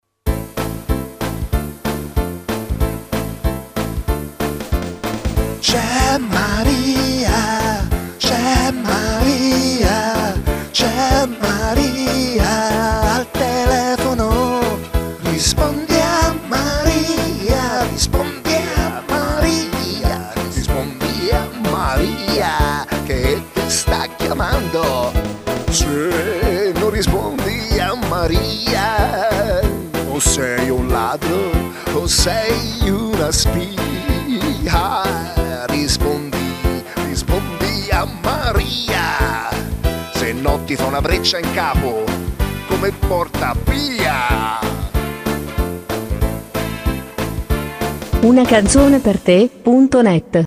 Una suoneria personalizzata